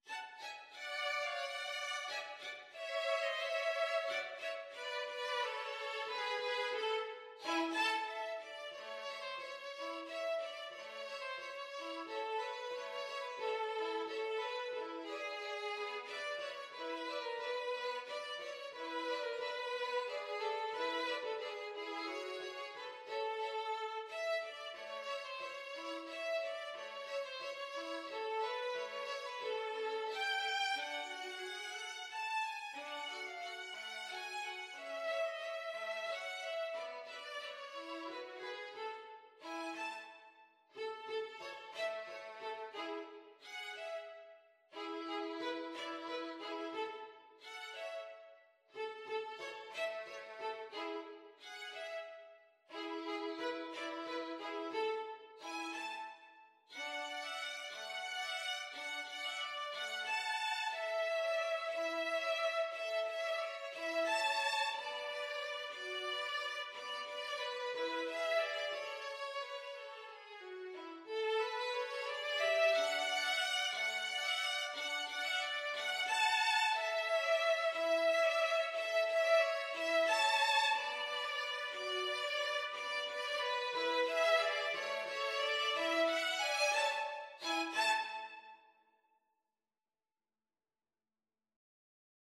Traditional Music of unknown author.
One in a bar .=c.60
3/4 (View more 3/4 Music)